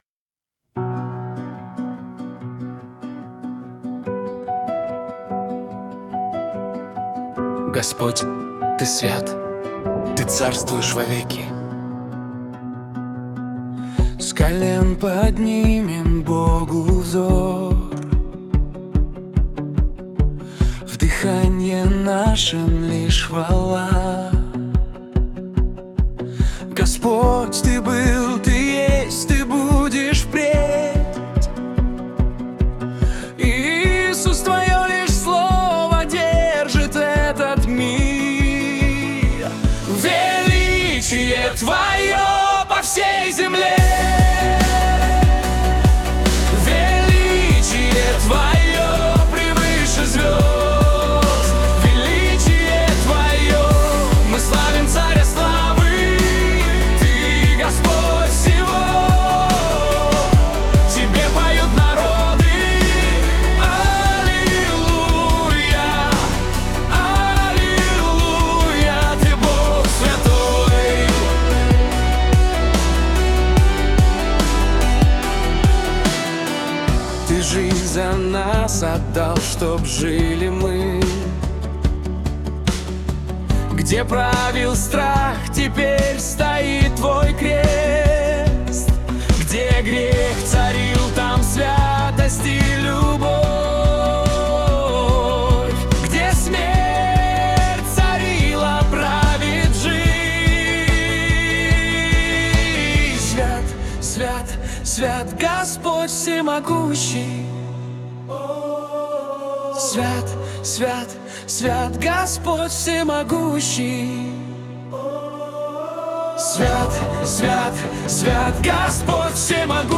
Нейросеть поёт Христу.
Представленные ниже песни были созданы с помощью нейронной сети на основе наших стихов